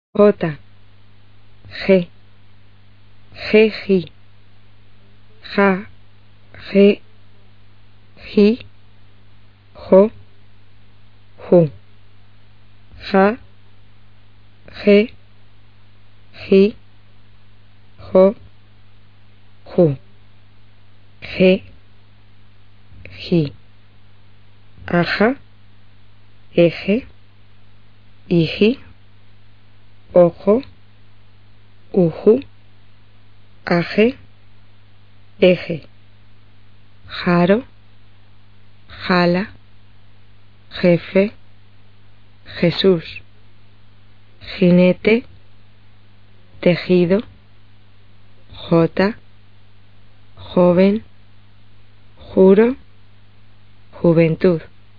g在与e,i组合，以及j在与所有元音组合时，都发[x]
[x]是舌后小舌擦清辅音。发音时，小舌向舌后下垂，两者之间形成缝隙，让气流通过。声带不振动。